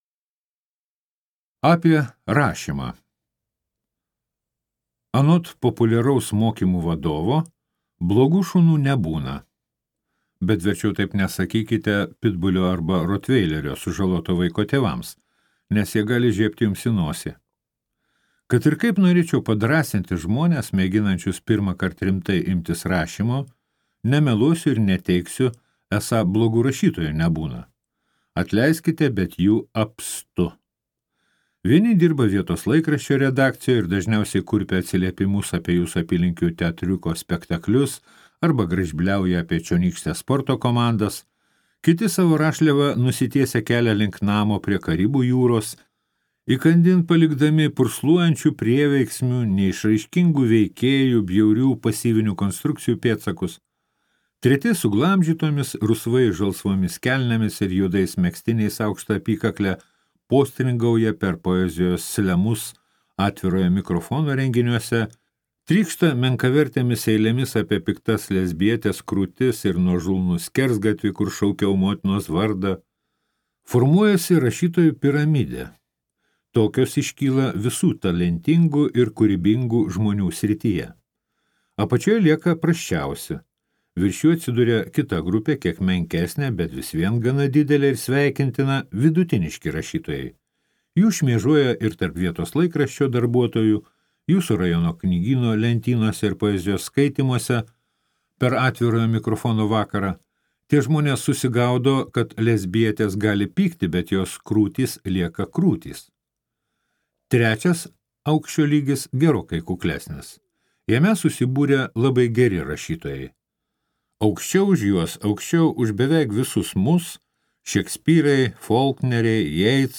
Memuarai apie amatą | Audioknygos | baltos lankos